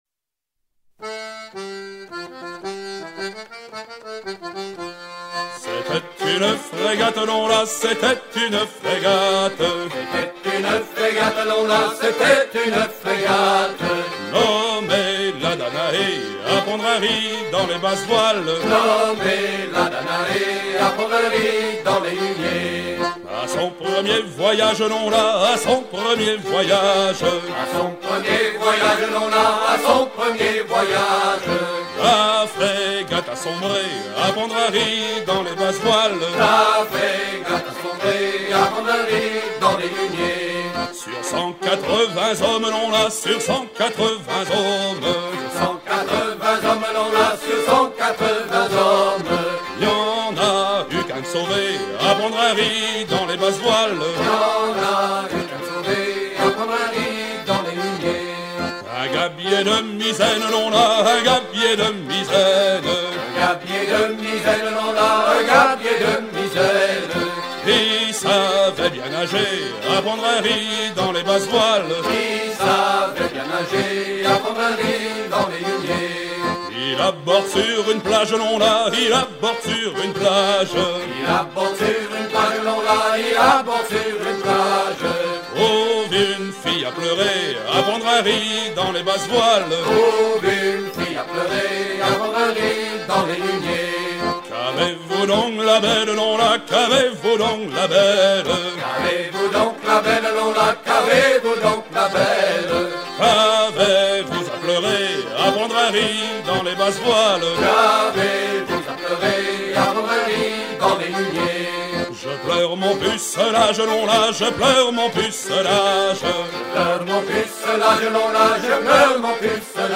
chants du gaillard d'avant
Pièce musicale éditée